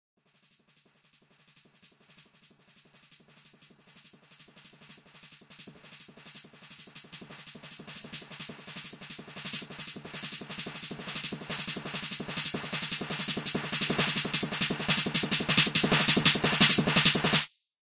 描述：在永久的过滤器变化下，极快地重复鼓声。在非常强烈的高潮中出现各种重音。
Tag: 过滤器 噪声 重复